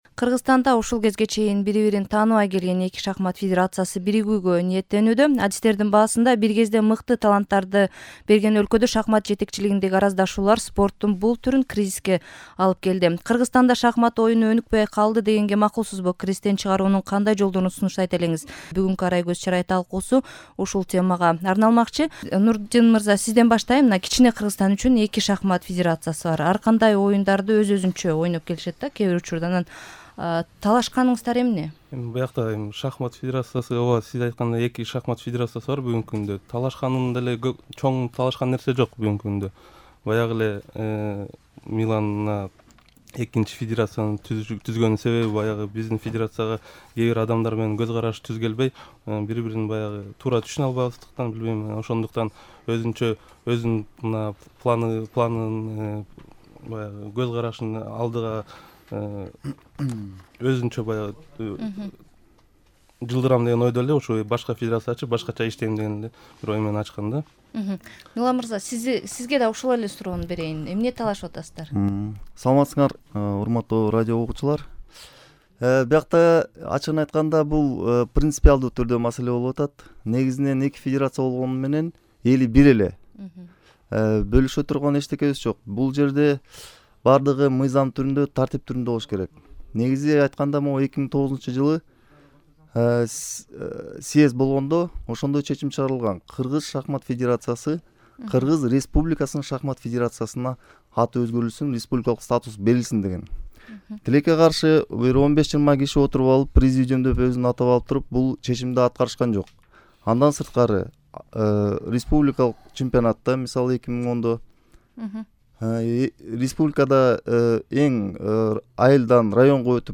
Шахмат тууралуу талкуу (1-бөлүк)